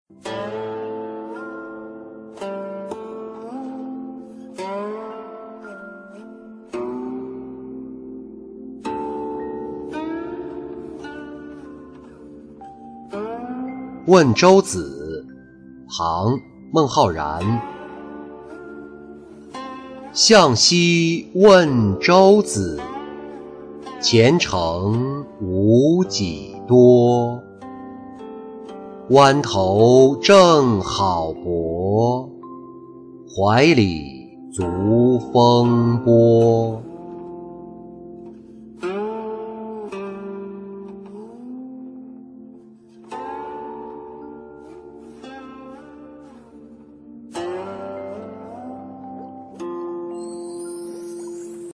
问舟子-音频朗读